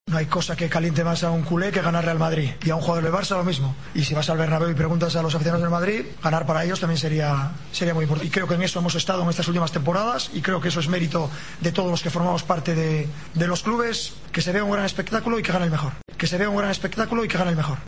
"No hay cosa que caliente más a un culé que ganar al Real Madrid. Y al revés, claro. Dentro de los parámetros deportivos espero que se vea un gran espectáculo y que gane el mejor", aseguró Luis Enrique, en la rueda de prensa previa al clásico en el Camp Nou.